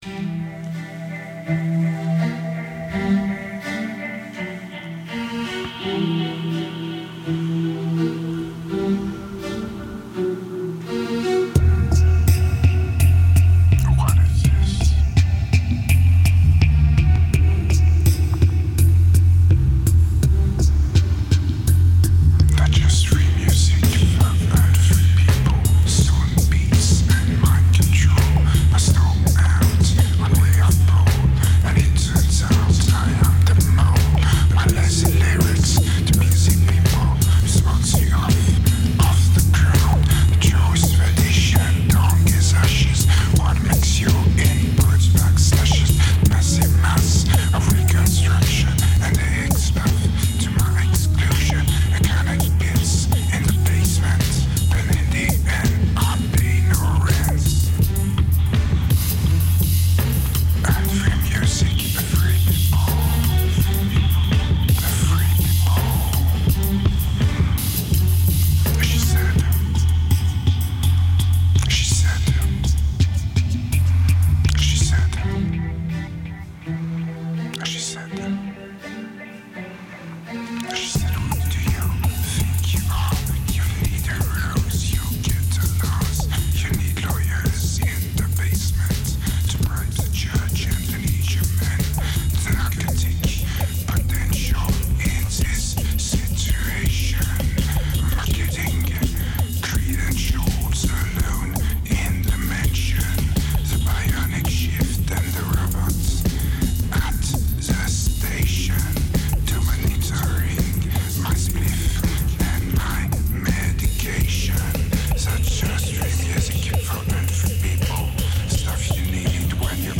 Interlude Drums Useless Section Break Bare Questions